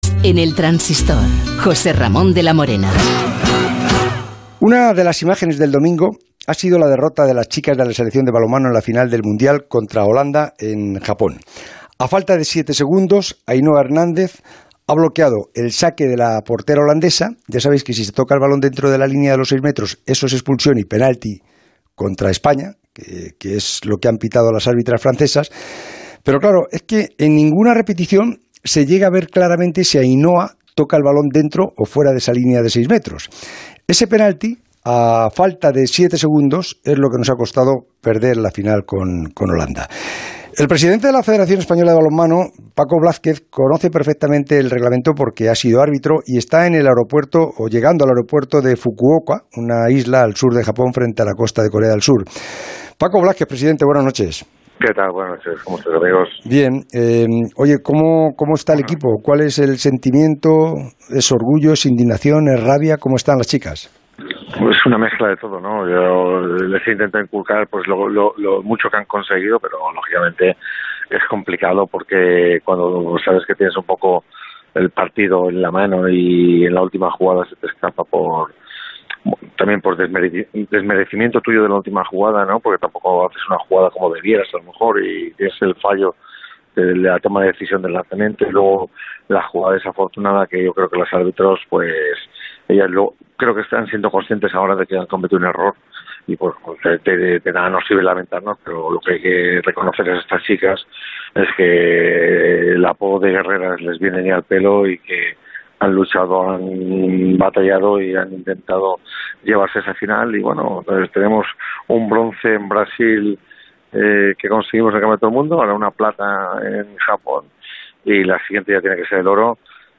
Pues bien, el presidente de la Federación Española de Balonmano, Francisco Blázquez, en declaraciones a El Transistor de Onda Cero, afirmó que la decisión de que no se utilizara la tecnología como auxilio a los árbitros en el Mundial la tomó el presidente de la Federación Internacional.